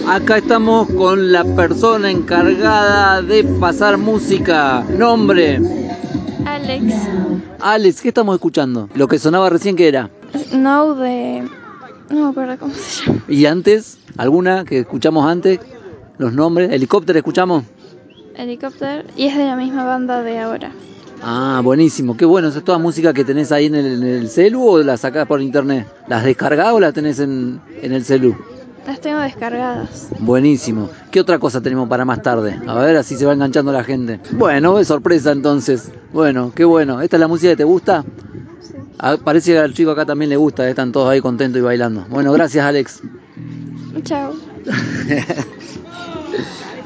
Radio del Monte estuvo presente en la fiesta que las vecinas y vecinos de Cumbre Azul organizaron para las niñas  y niños del barrio.